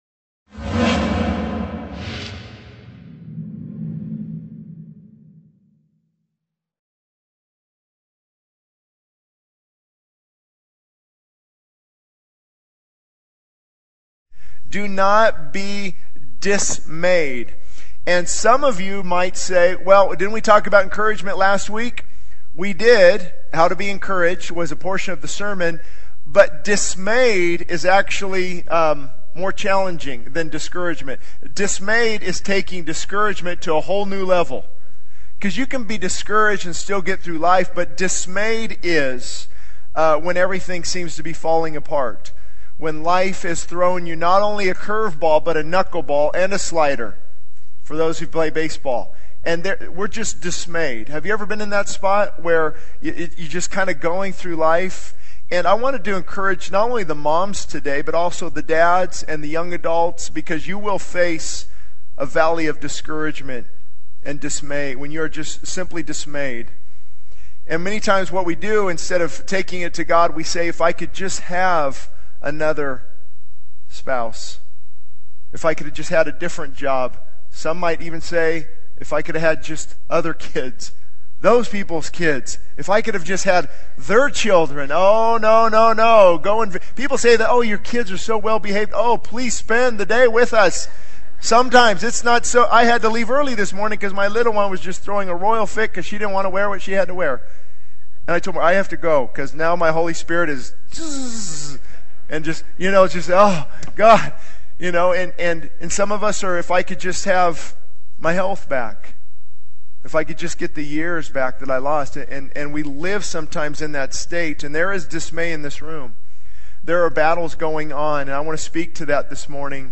The sermon concludes with a call to truly know God and to embrace His love and strength in our struggles.